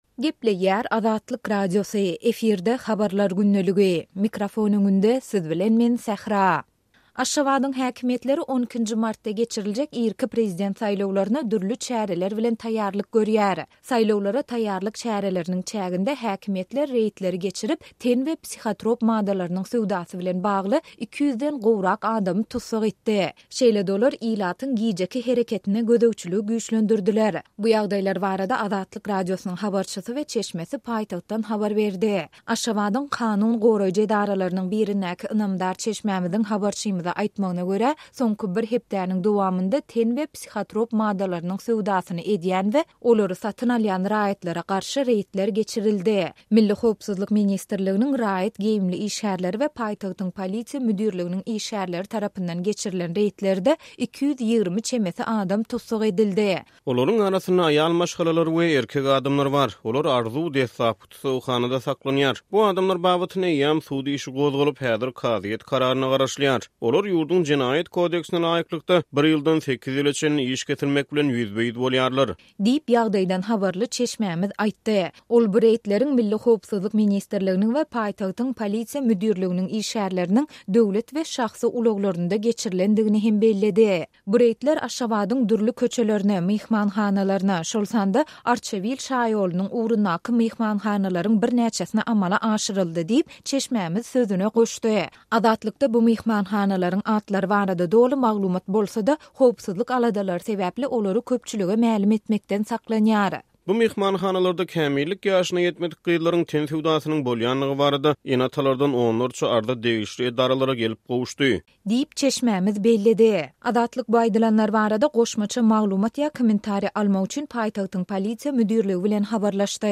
Bu ýagdaýlar barada Azatlyk Radiosynyň habarçysy we çeşmesi paýtagtdan habar berýär.